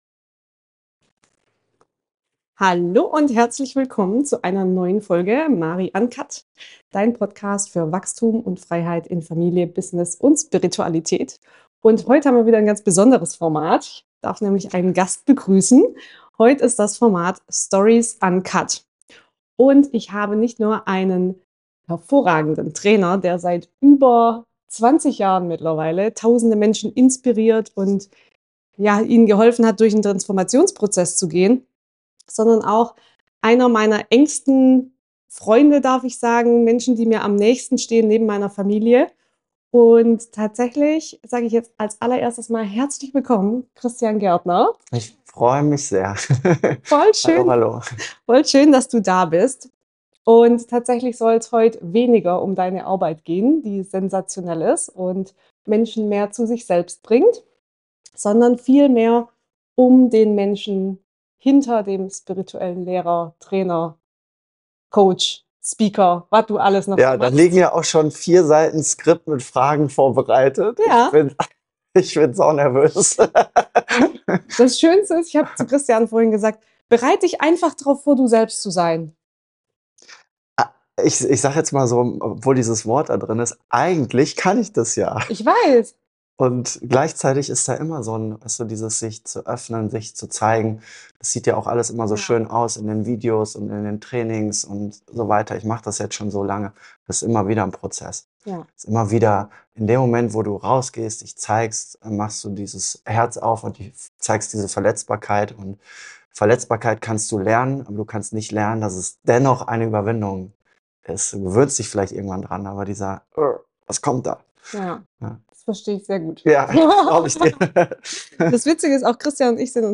Wie er Spiritualität lebt, wenn niemand zuschaut. Und welche Entscheidungen sein Leben verändert haben. Ein ehrliches, tiefes Gespräch über Mut, Menschlichkeit, Herzöffnung und die Reise zurück zu sich selbst.